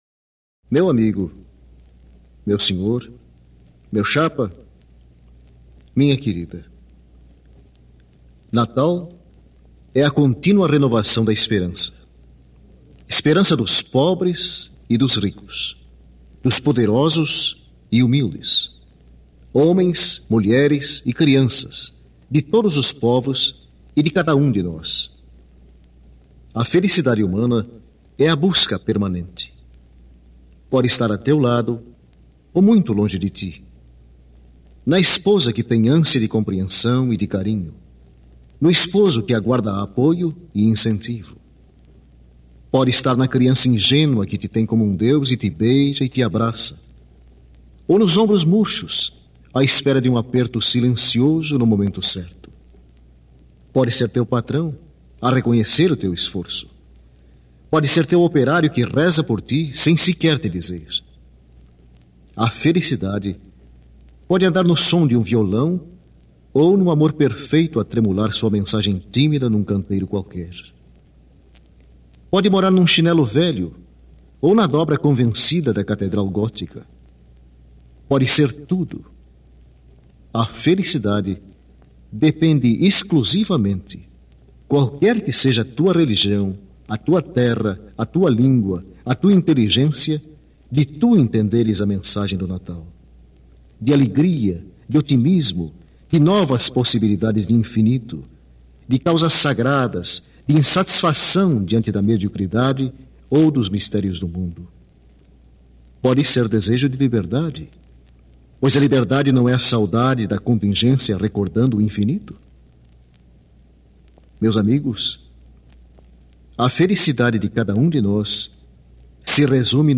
interpreta canções natalinas internacionais